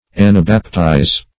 Search Result for " anabaptize" : The Collaborative International Dictionary of English v.0.48: Anabaptize \An`a*bap*tize"\, v. t. [Gr.